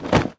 摔倒-YS070515.wav
通用动作/01人物/01移动状态/摔倒-YS070515.wav